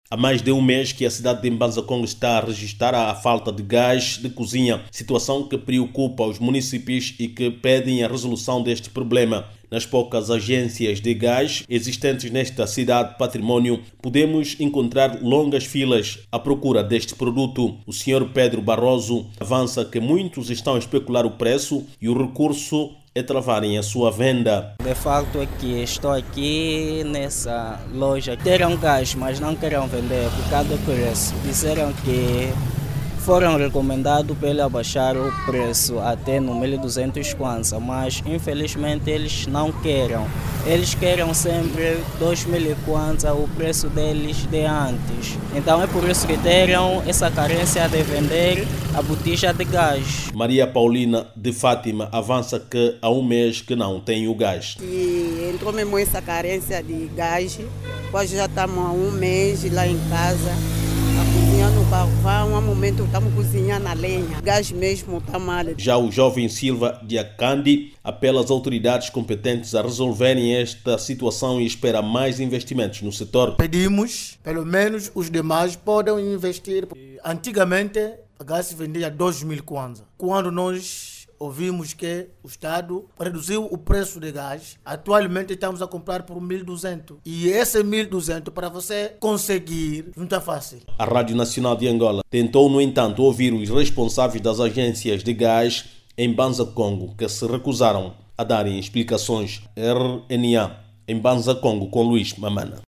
A província do Zaire, regista escassez de gás de cozinha há mais de três semanas, situação que deixa os munícipes preocupados. Nalgumas artérias de Mbanza Kongo,  regista-se longas filas a procura do líquido butano. Clique no áudio abaixo e ouça a reportagem